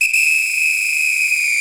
～【効果音】～
ぴぴー（終了）